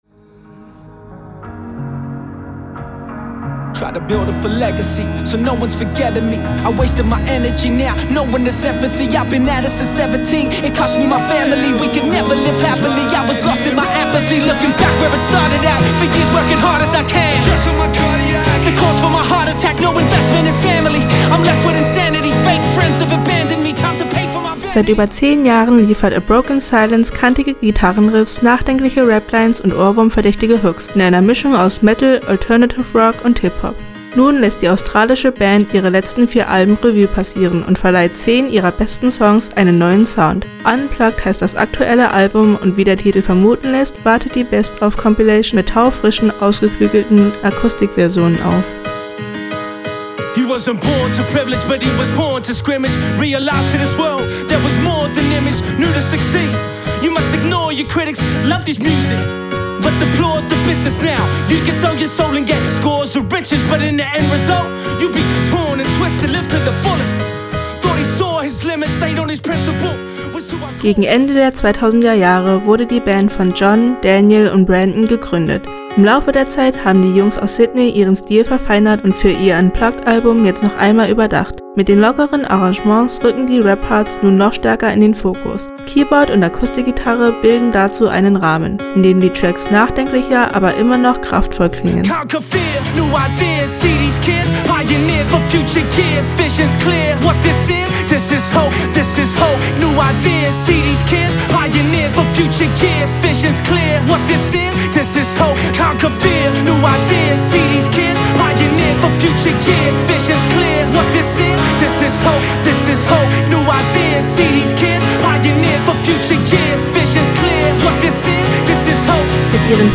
“Unplugged” heißt das aktuelle Album und wie der Titel vermuten lässt, wartet die Best-of-Compilation mit taufrischen, ausgeklügelten Akustikversionen auf.
Mit den lockeren Arrangements rücken die Rap-Parts nun noch stärker in den Fokus. Keyboard und Akustikgitarre bilden dazu einen Rahmen, in dem die Tracks nachdenklicher, aber immer noch kraftvoll klingen.